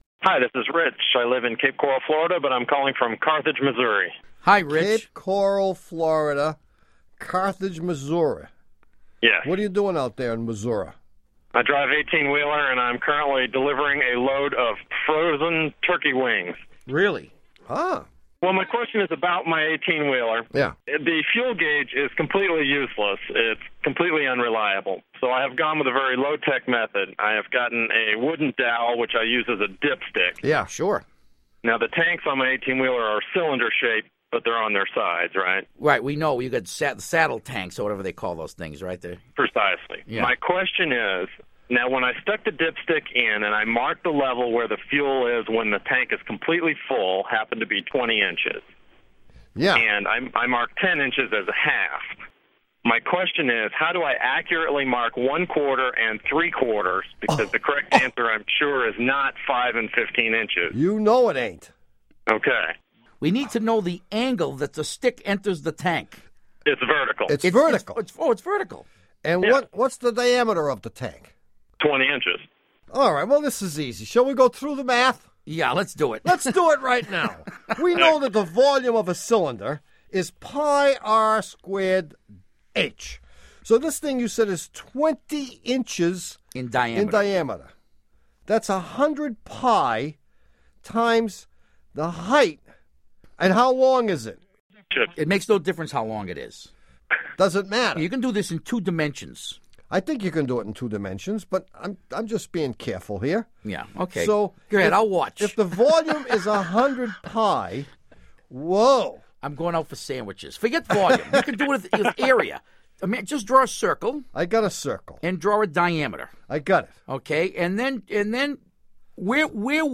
One of the show hosts: [show page,